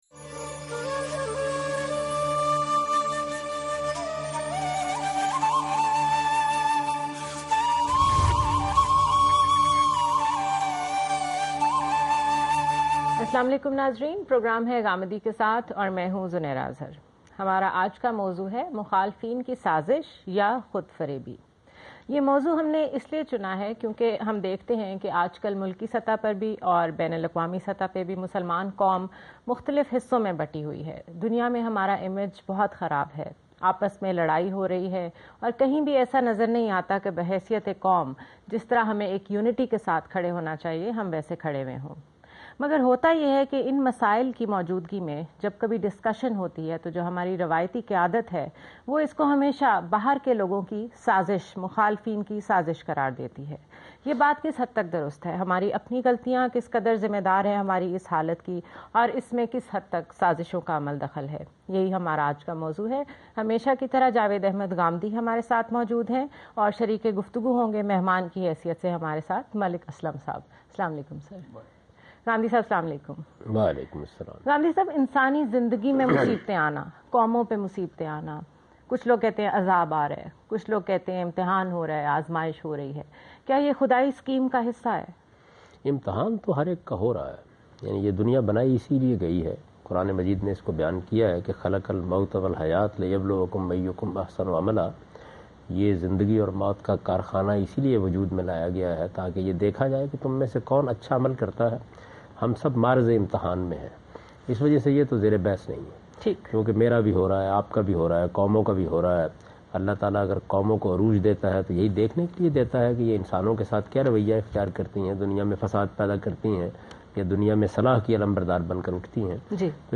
Javed Ahmad Ghamidi is discussing about Enemy Conspiracies or Self-Deception in Samaa Tv's program Ghamidi Kay Saath.